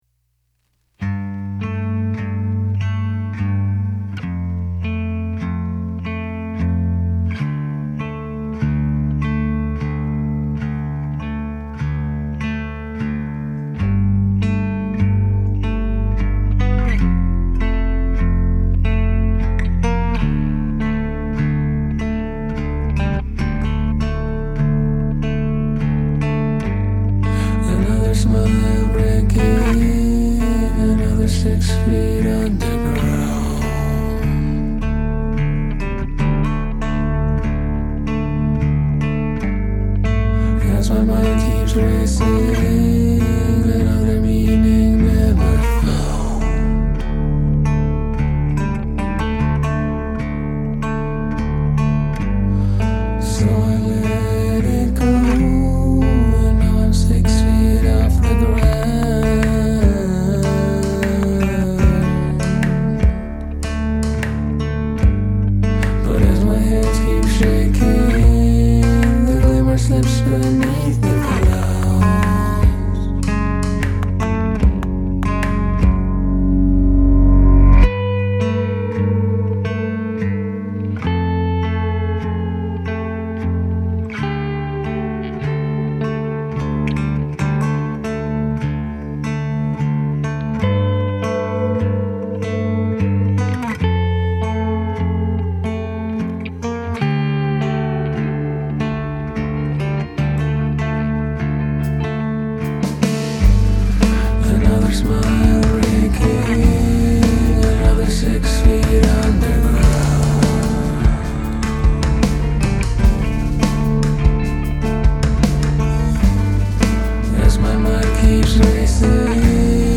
Gesang und Rhythmusgitarre
Schlagzeug und Backing-Vocals
Lead-Gitarre und Backing-Vocals